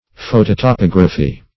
Search Result for " phototopography" : The Collaborative International Dictionary of English v.0.48: Phototopography \Pho`to*to*pog"ra*phy\, n. Photogrammetry.
phototopography.mp3